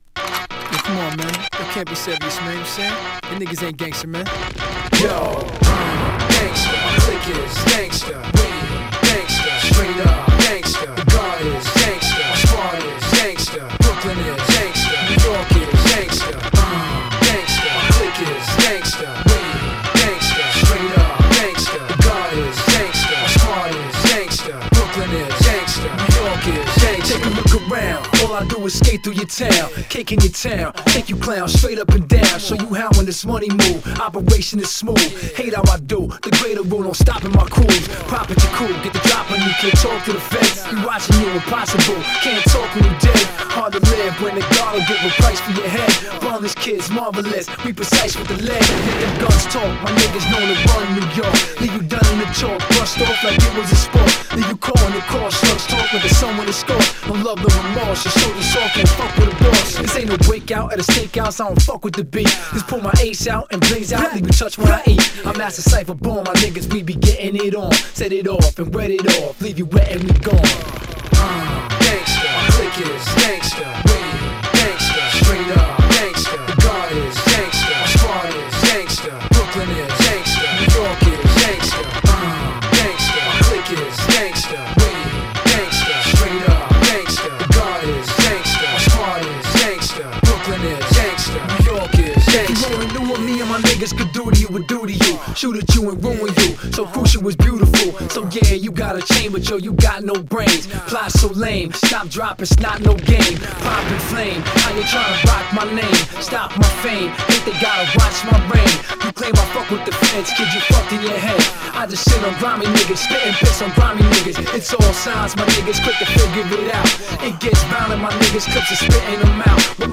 2. > HIPHOP
当時流行った45rpmサンプリングを使用！